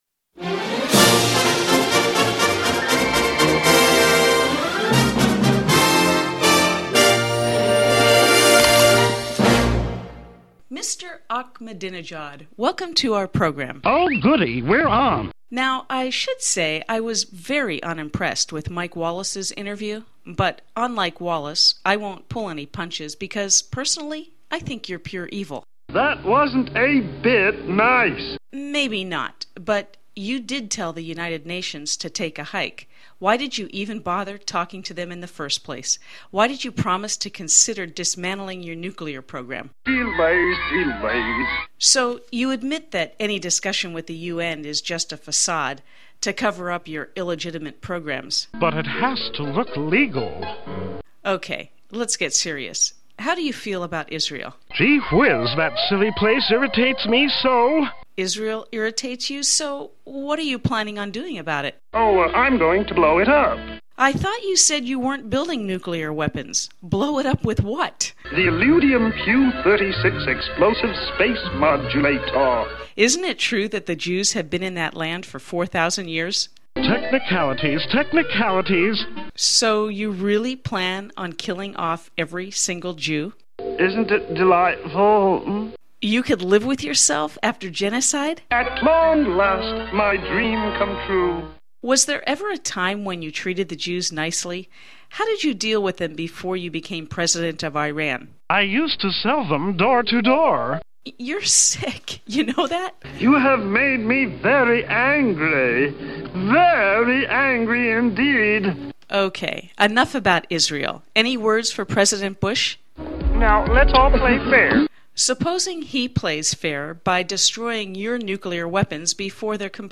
Interview With Ahmadinejad